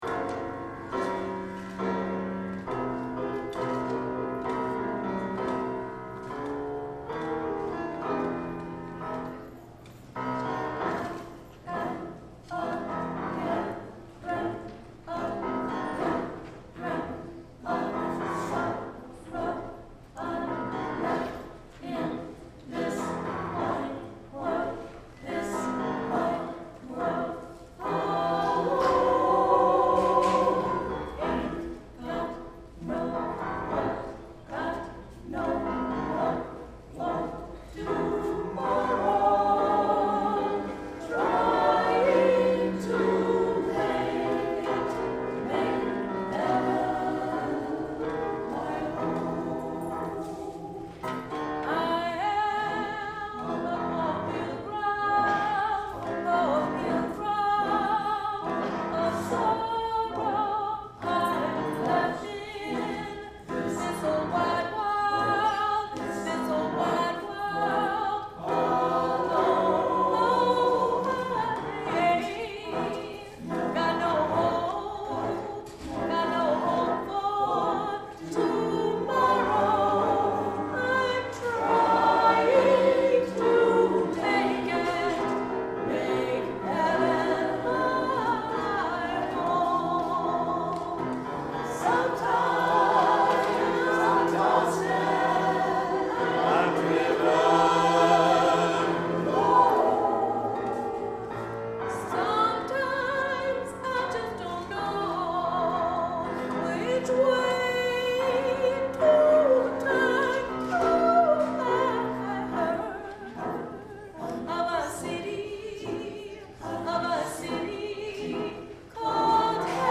For 10 years, Tangy Voce, a chorus made up of current and retired faculty and staff, has offered its members an opportunity to grow as musicians and gain confidence as singers.